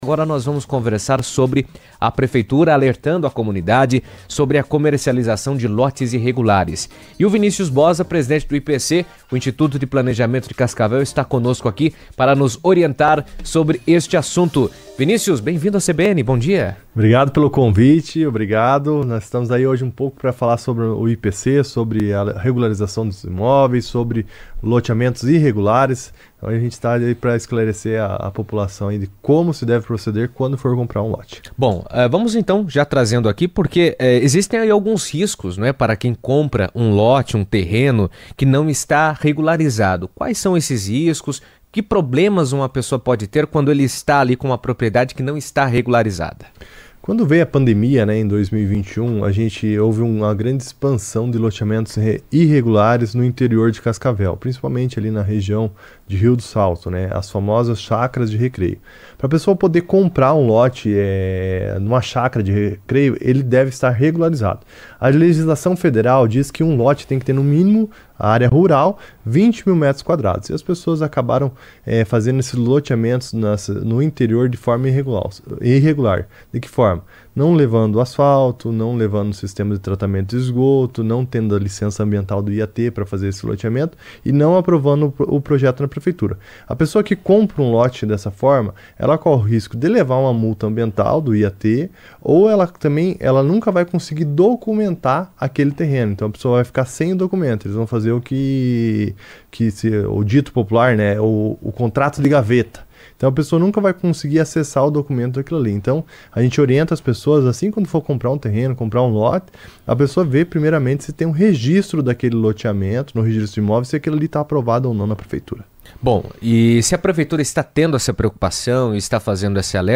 Terrenos irregulares em Cascavel têm gerado preocupação quanto à segurança jurídica e ao planejamento urbano. De acordo com Vinicius Boza, presidente do IPC (Instituto de Planejamento de Cascavel), a venda de imóveis em loteamentos não regularizados coloca em risco mais de mil famílias e pode trazer problemas legais e financeiros para os compradores. Em entrevista à CBN, ele destacou a importância de verificar a regularidade dos terrenos antes de qualquer negociação.